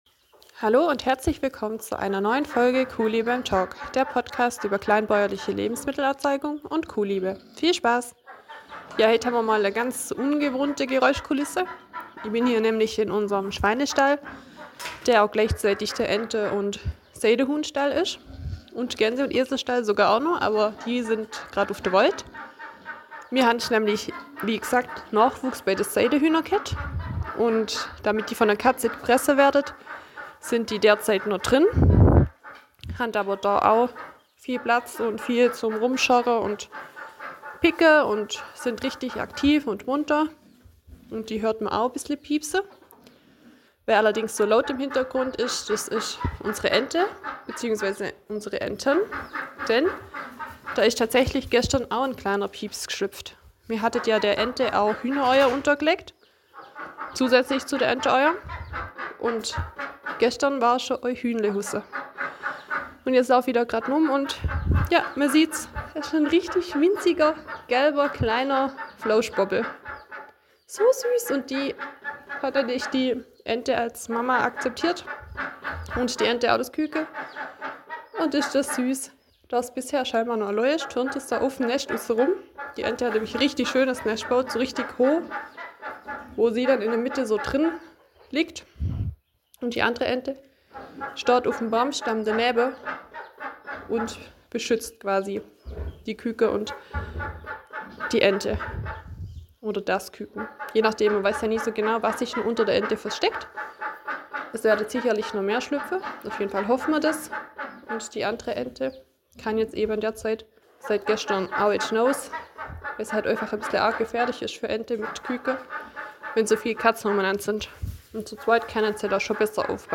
about-last-week-live-aus-dem-schweinestall.mp3